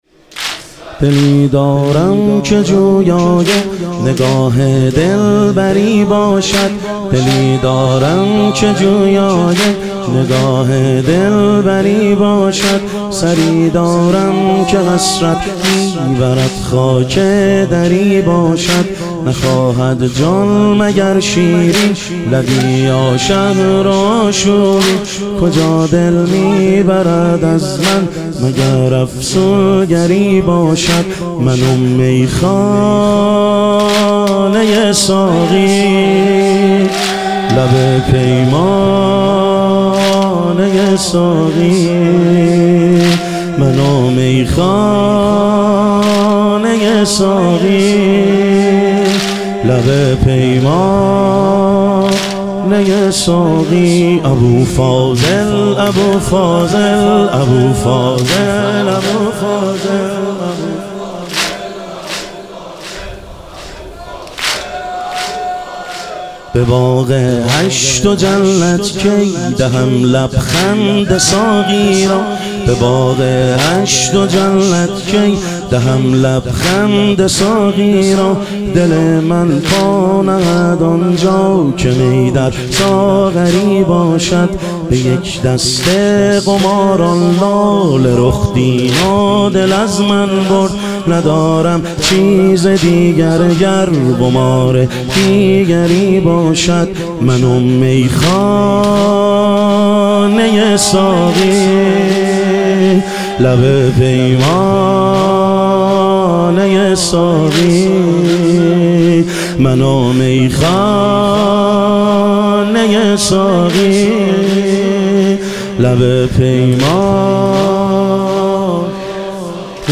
محرم الحرام - واحد